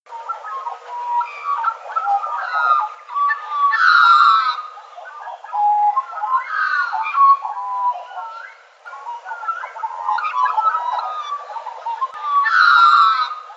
magpie2.mp3